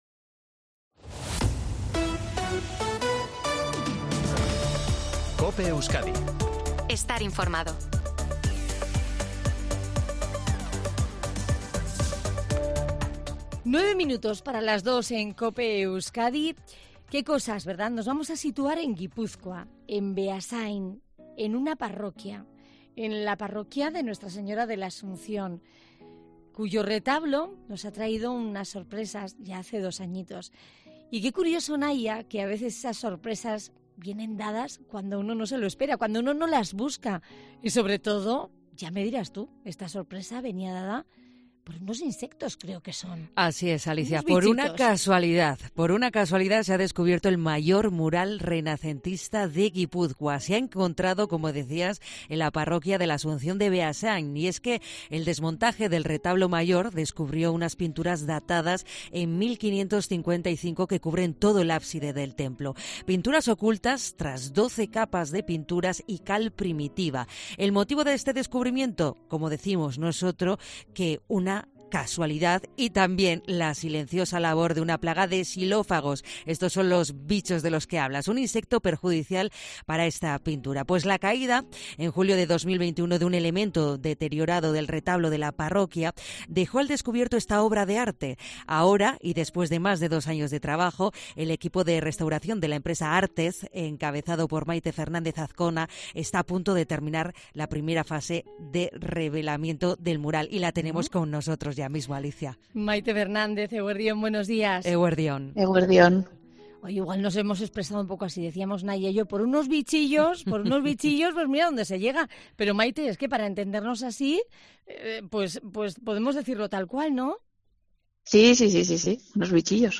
se ha pasado por los micrófonos de COPE Euskadi para detallar las claves de este gran descubrimiento